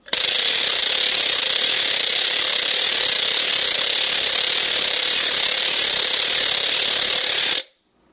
棘轮" 棘轮连续中号01
描述：专业管弦乐木制棘轮打击乐器的声音。以中等速度连续演奏。
Tag: 弹出 点击 按扣 棘轮 管弦乐 打击乐